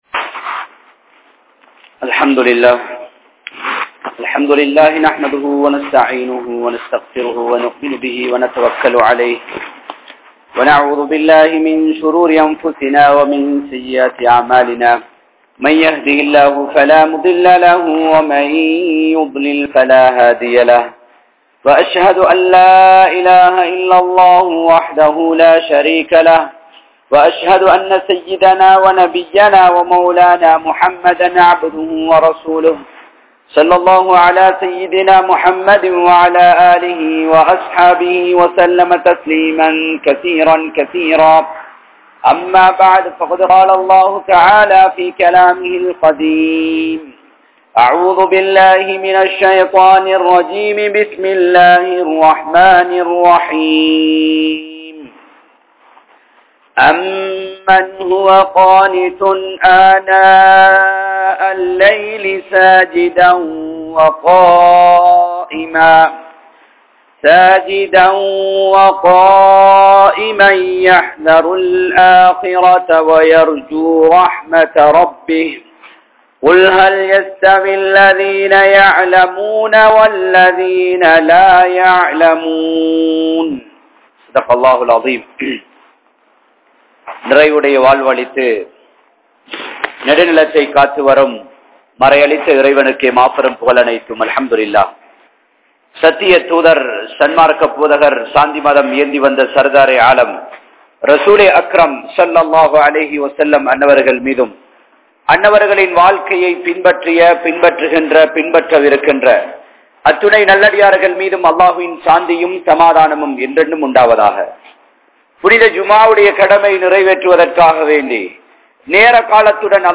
Indraya Muslimkalin Kalvi Nilai | Audio Bayans | All Ceylon Muslim Youth Community | Addalaichenai
Badhriyeen Jumua Masjith